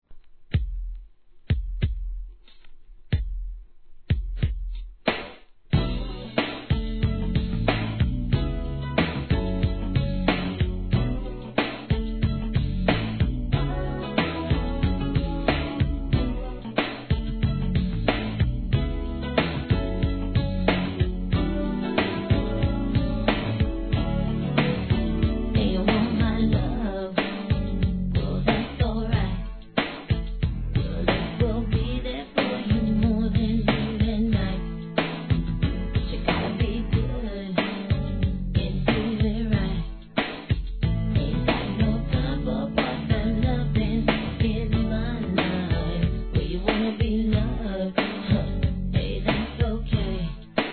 HIP HOP/R&B
女の子だけを第一義に作られたスーパー・ラブリー・テンダー6曲いり!!